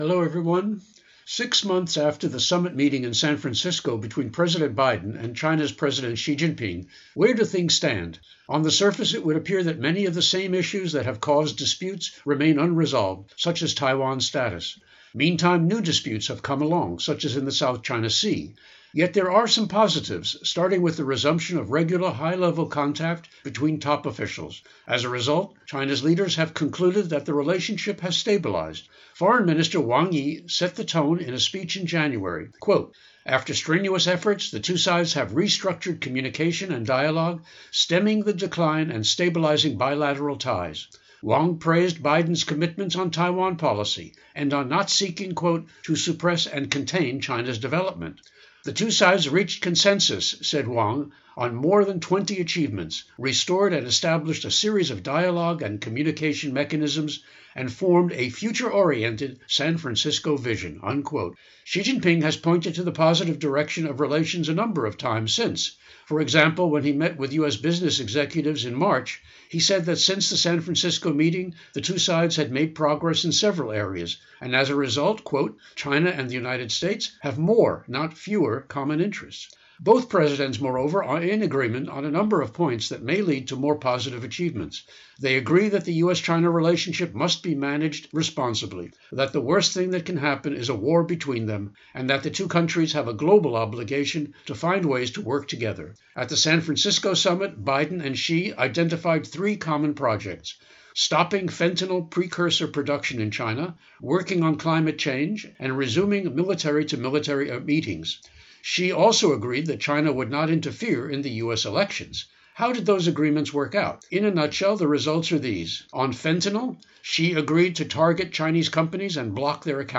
Public Affairs